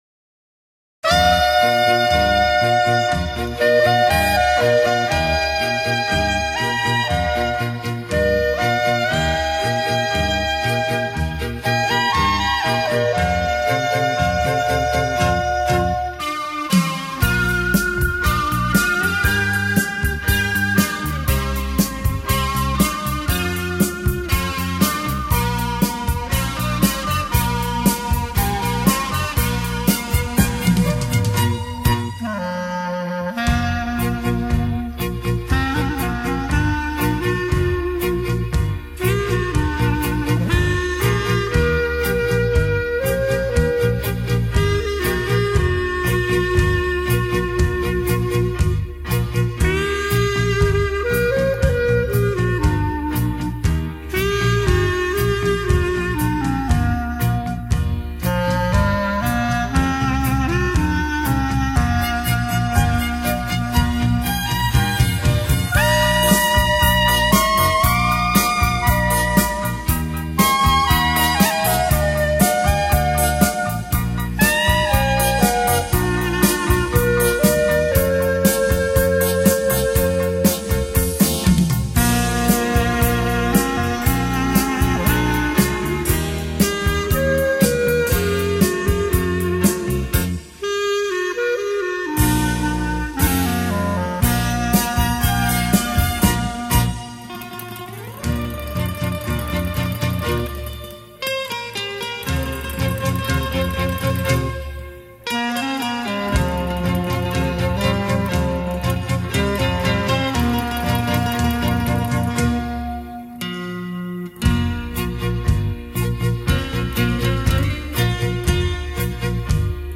最顶尖的一套百年黑管V.S萨克斯风演奏杰作